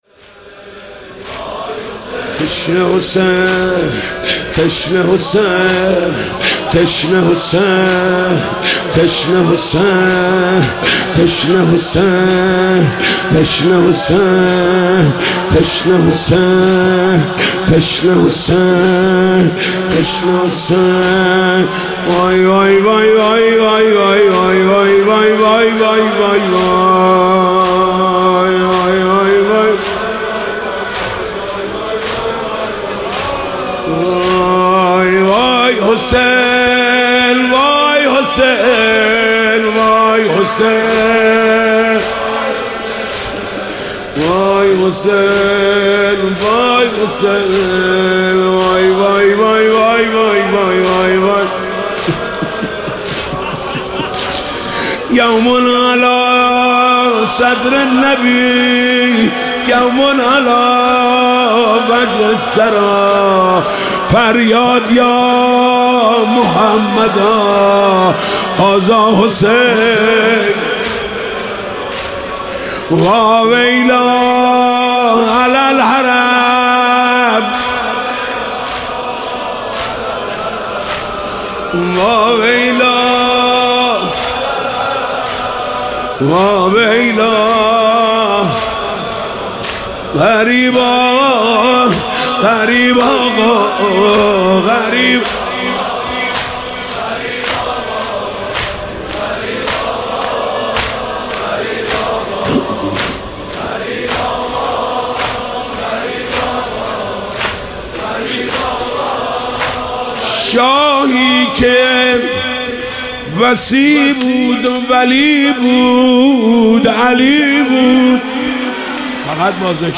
شام غریبان